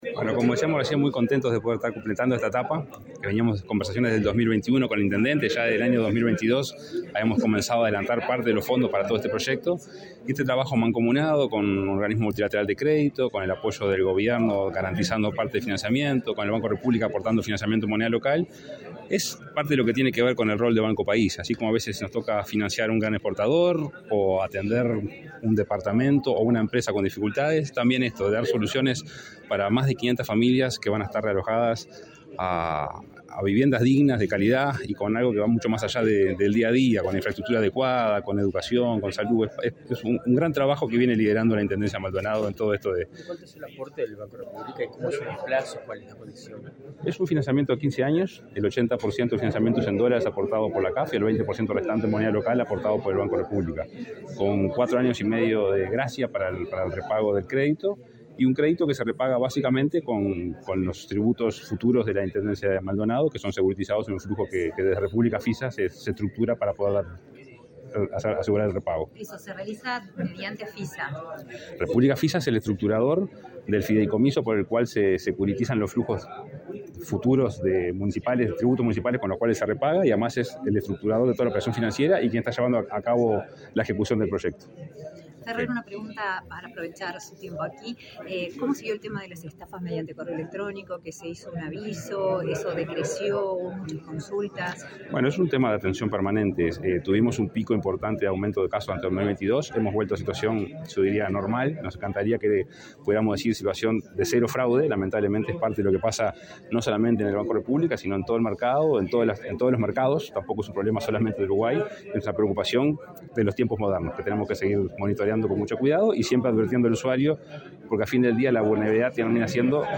Declaraciones a la prensa del presidente del BROU, Salvador Ferrer